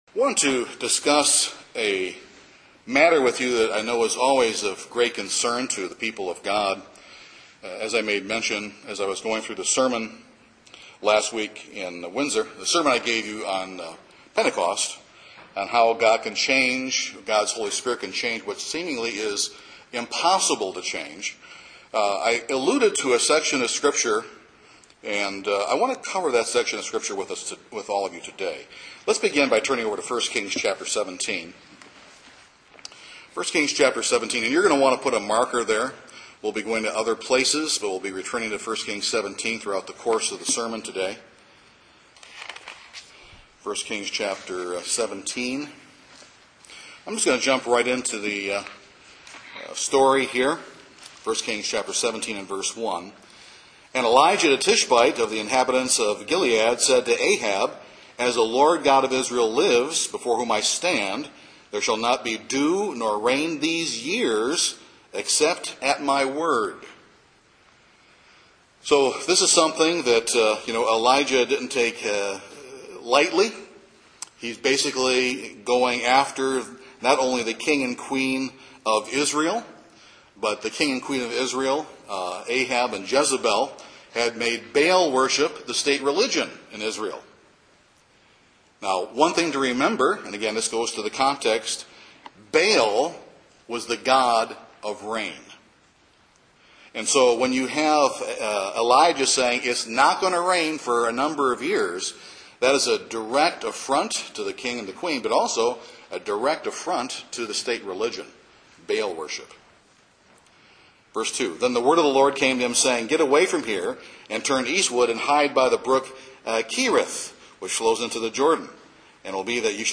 This sermon examines a chapter in the life of a man and woman under tremendous pressure and in great trial. Their faithful response to desperate times should encourage us in our faithful walk with God in desperate times.